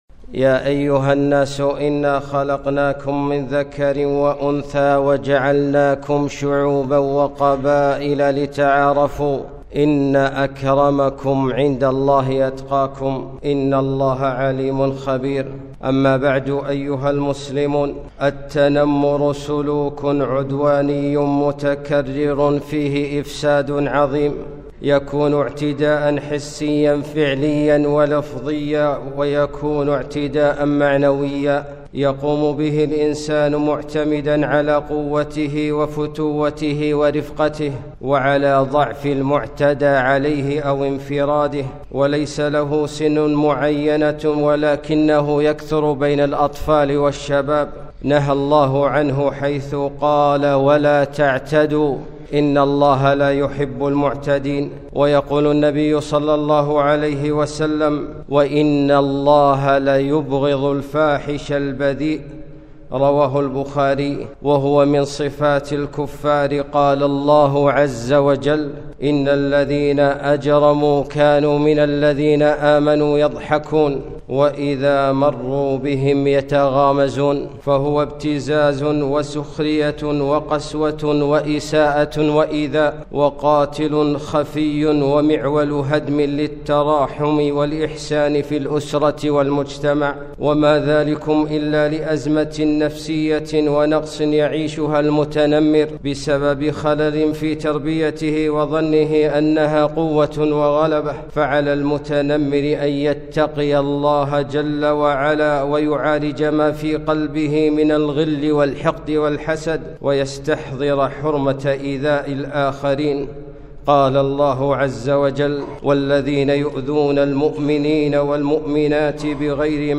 خطبة - التنمر